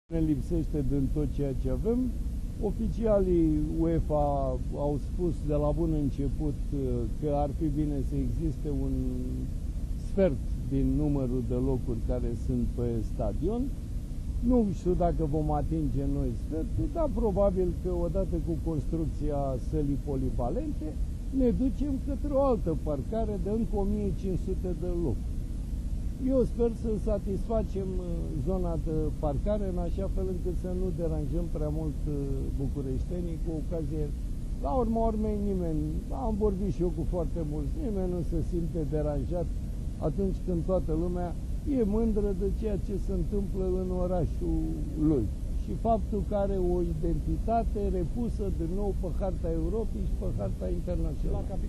Primarul Capitalei, Sorin Oprescu, a afirmat ca, dupa ce evenimentul fotbalistic din 2012 a pus Romania pe harta sportiva a Europei, nominalizarea pentru 2020 vine ca un lucru firesc.